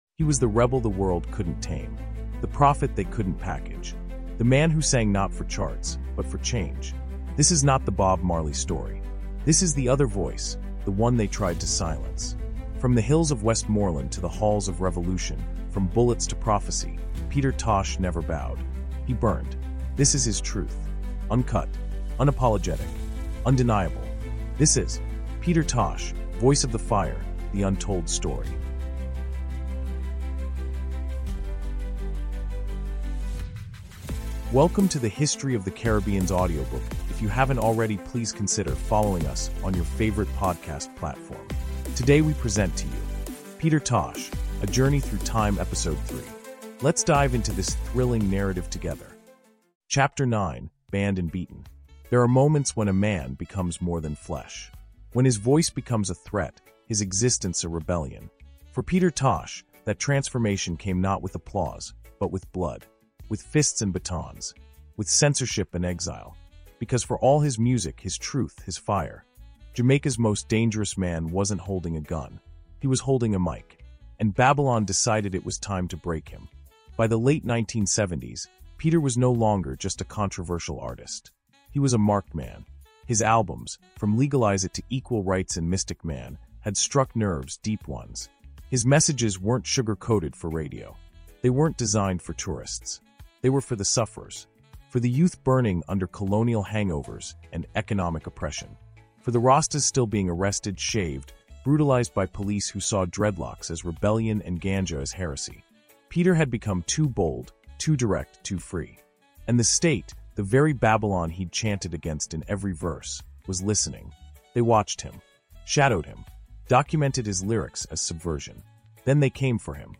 Peter Tosh: Voice of the Fire – The Untold Story is a gripping 16-chapter cinematic audiobook that resurrects the life, music, and martyrdom of one of reggae’s fiercest revolutionaries. From his rise with The Wailers to his assassination in 1987, this raw and emotionally charged narrative exposes the industry sabotage, political conspiracies, and spiritual battles behind the man who refused to bow. Featuring detailed storytelling written for AI narration, this audiobook is more than a biography—it’s a rebellion in words.